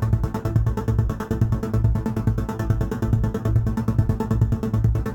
Index of /musicradar/dystopian-drone-samples/Tempo Loops/140bpm
DD_TempoDroneC_140-A.wav